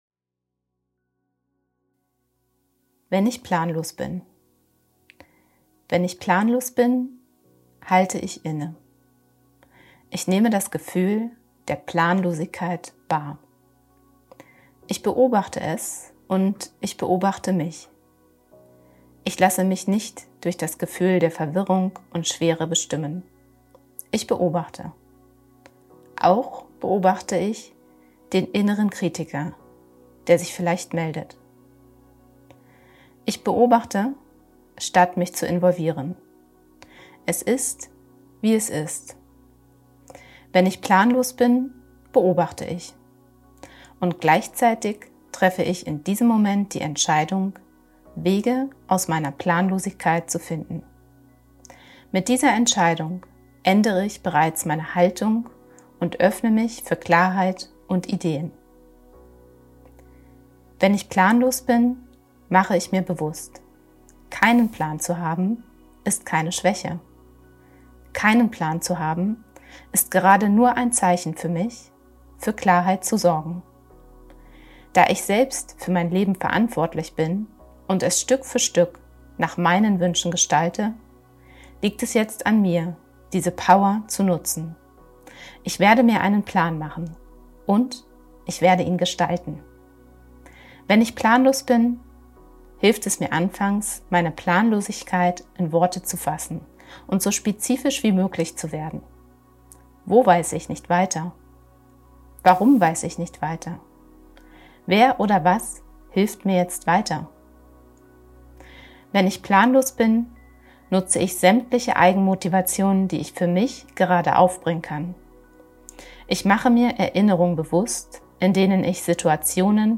Wenn-ich-planlos-bin_mit-Musik.mp3